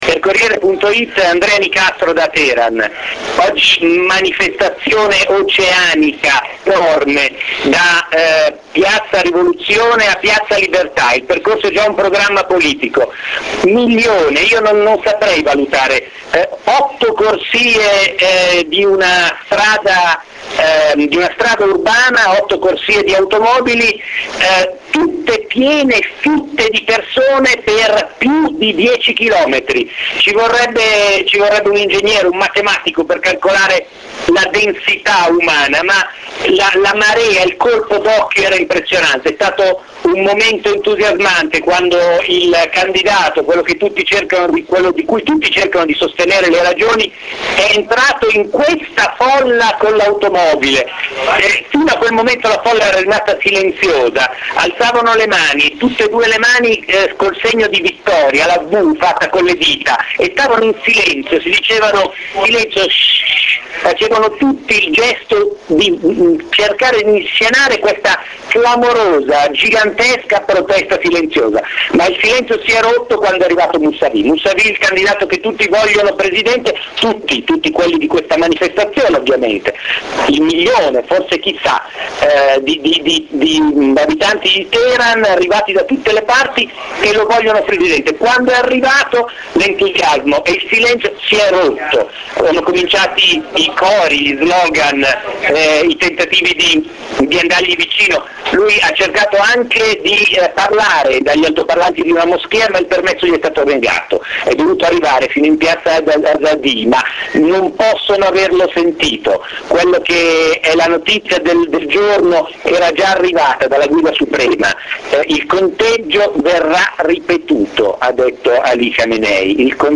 Teheran 2009, manifestazione pro-Mussavi (mp3)
teheran2009-manif-pro-mussavi-audio.mp3